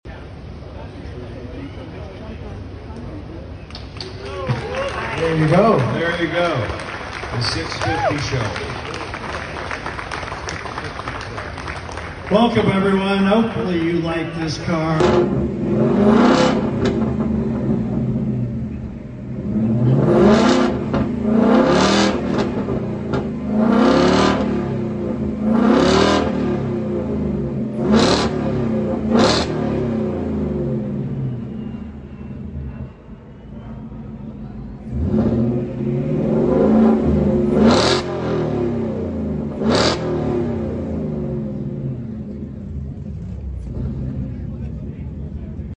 Shelby Super Snake 650 sound effects free download
Mp3 Sound Effect Shelby Super Snake 650 - comes with 800hp, carbon fenders, Whipple supercharger, bigger brakes, rotors, magnesium wheels, Borla exhaust…